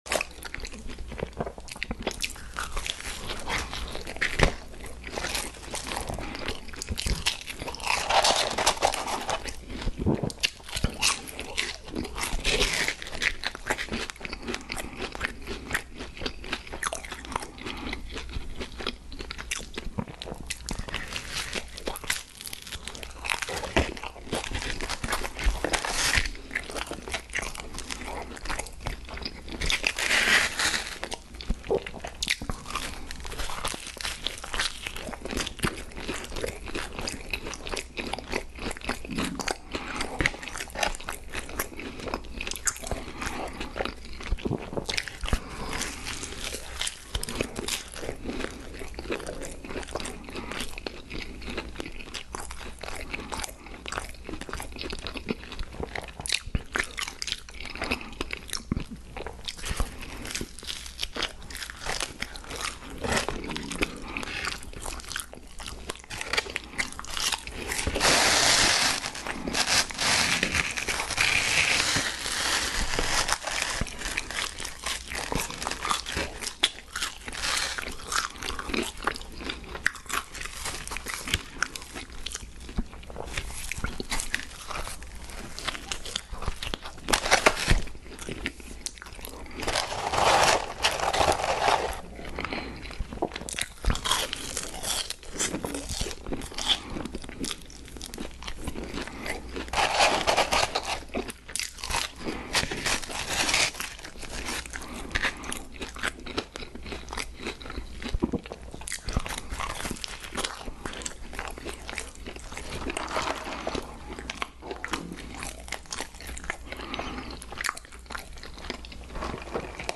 ASMR DOMINO'S PIZZA AND WENDY'S sound effects free download
ASMR DOMINO'S PIZZA AND WENDY'S FRIES MUKBANG EATING NO TALKING SOUNDS COMMERCIAL 🍕- Part 2